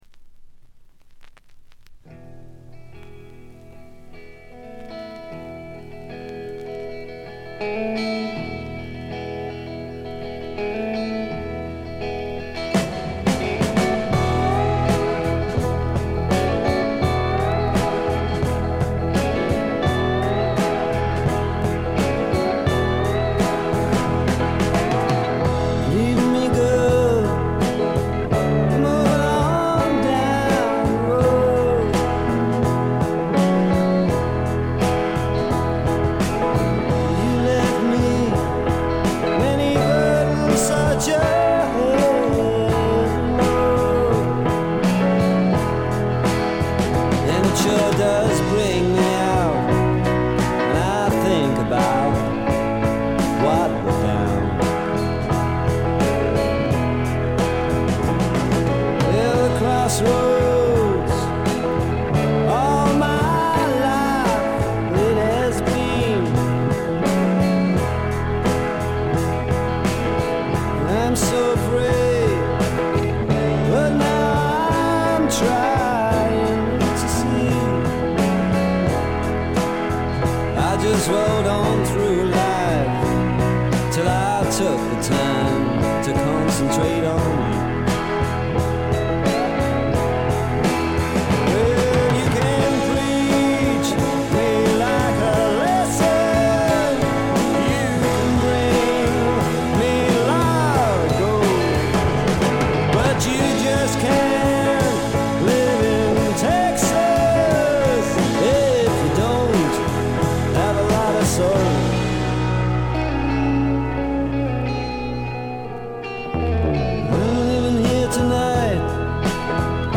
部分試聴ですが、微細なノイズ感のみ、極めて良好に鑑賞できると思います。
試聴曲は現品からの取り込み音源です。